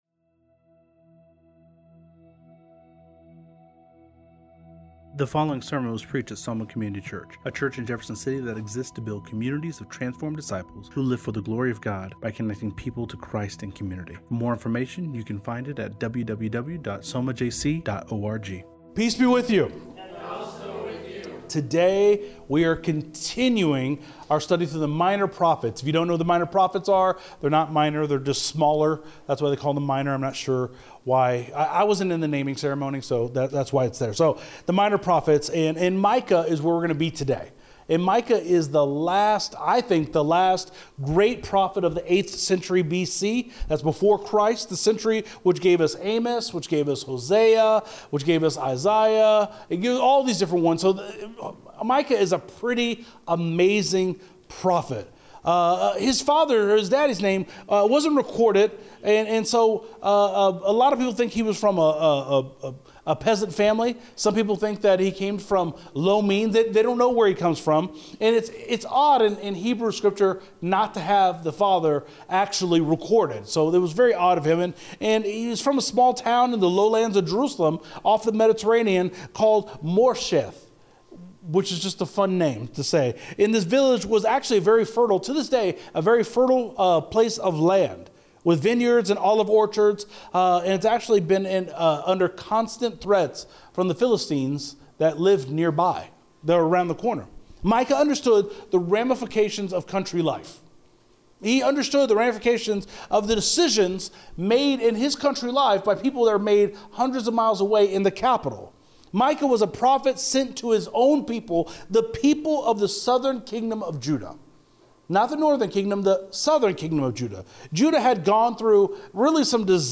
Soma Community Church in Jefferson City, MO on Sunday morning 16 June 2024